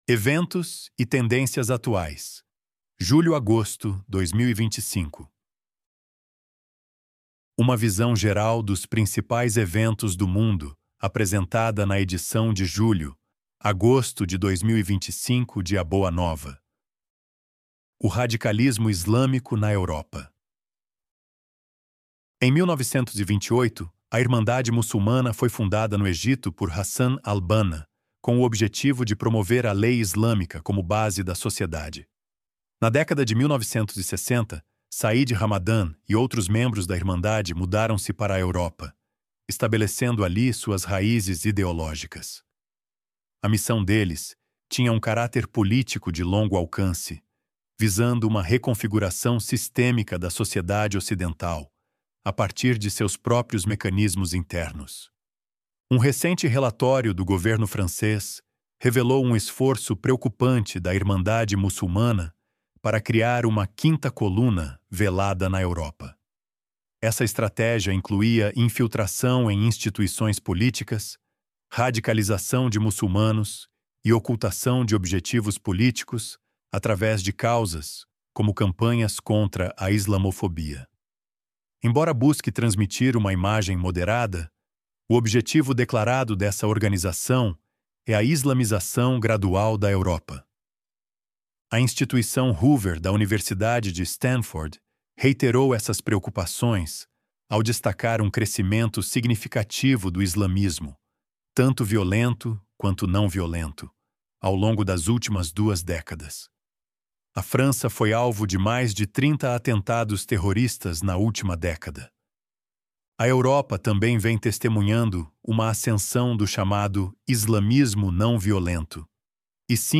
ElevenLabs_Eventos_e_Tendências_Atuais_Julho-Agosto_2025.mp3